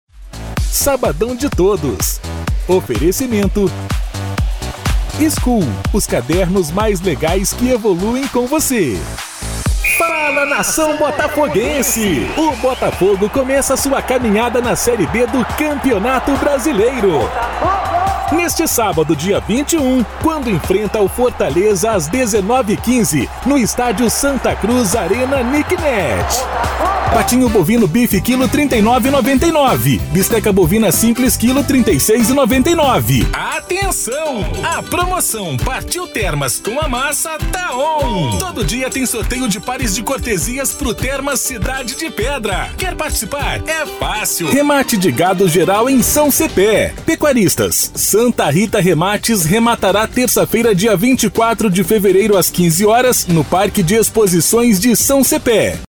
Varejo: